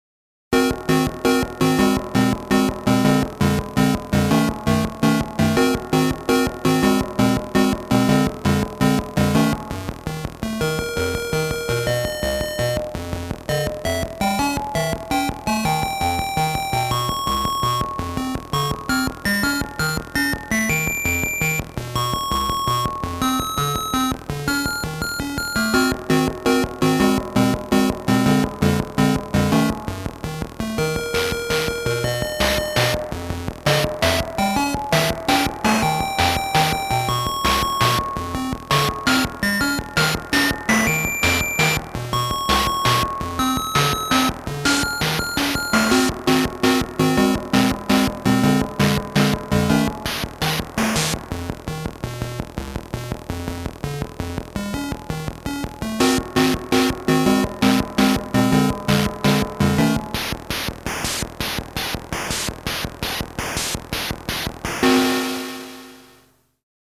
Atari-ST Tunes